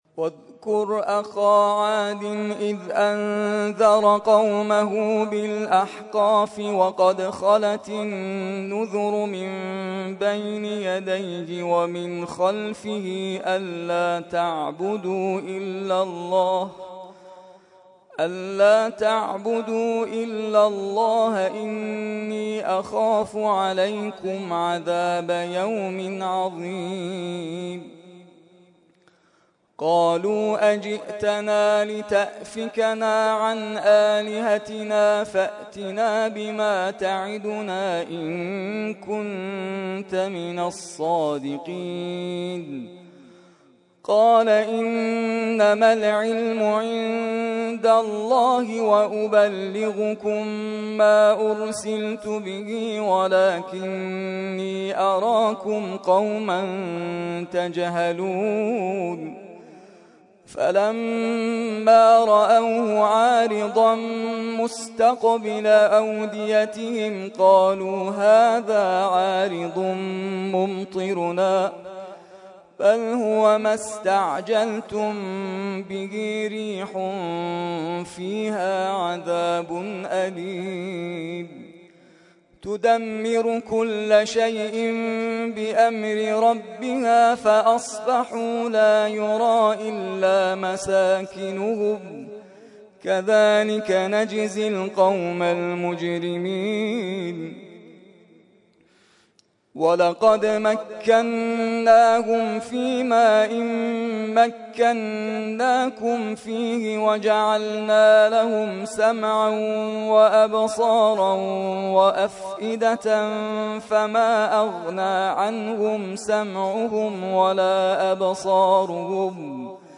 ترتیل خوانی جزء ۲۶ قرآن کریم در سال ۱۳۹۴